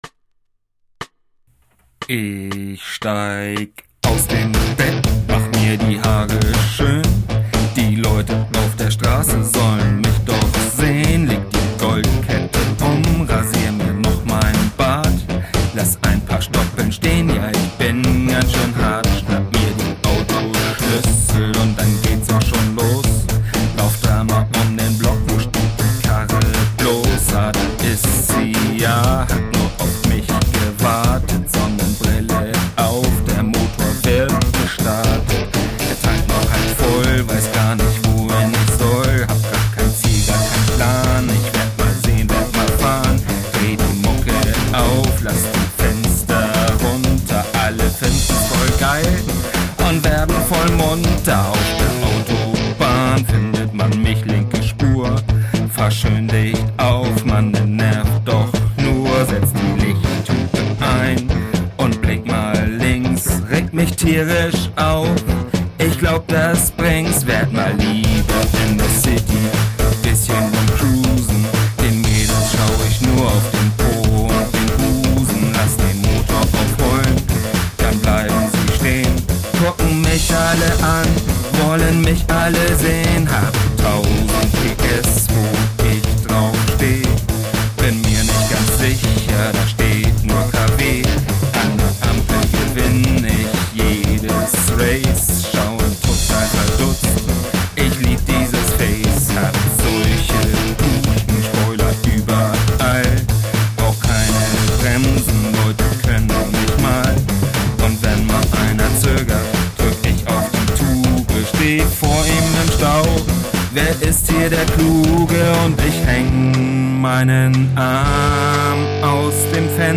Hier das Beispiel mit neuem Gesang.
Der Gesang ist irgendwie übersteuert, das müsste ich neu machen, geht jetzt erstmal nur um den Ausdruck.